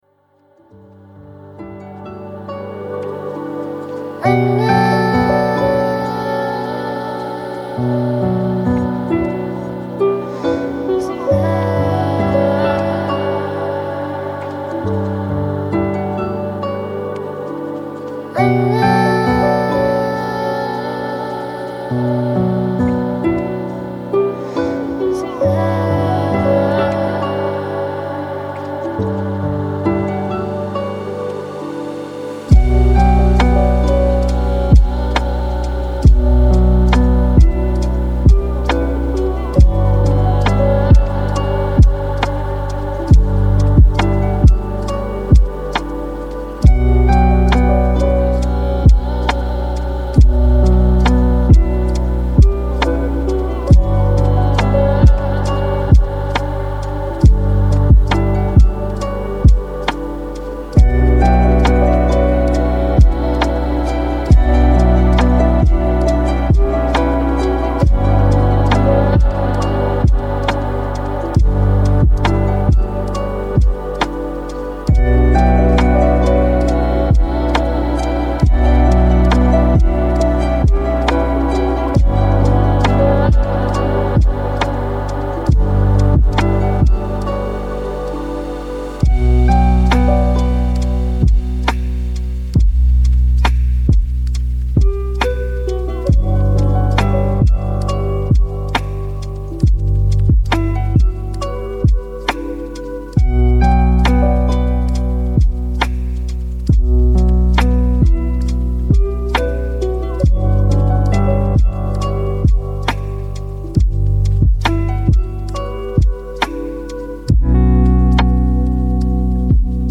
Guérison 528 Hz : Corps et Esprit